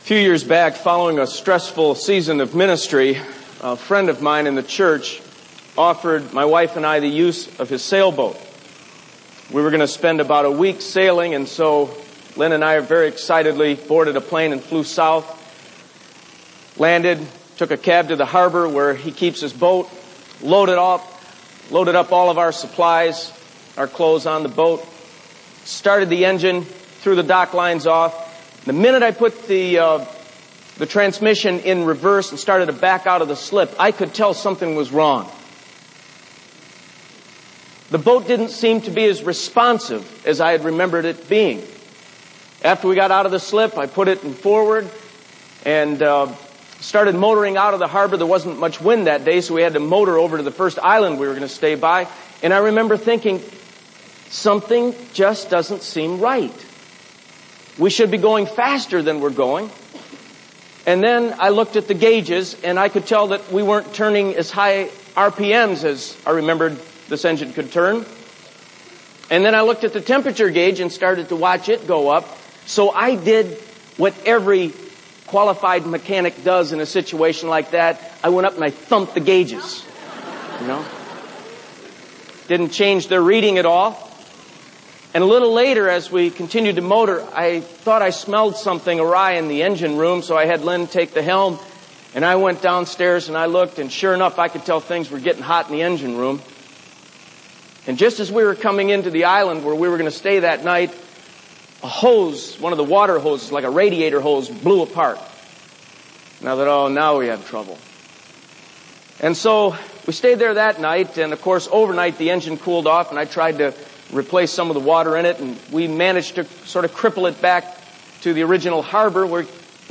The following messages were given at Willow Creek Community Church quite a few years ago.